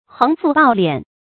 橫賦暴斂 注音： ㄏㄥˊ ㄈㄨˋ ㄅㄠˋ ㄌㄧㄢˇ 讀音讀法： 意思解釋： 橫、暴：殘暴，強橫，兇狠；賦、斂：征稅，聚財。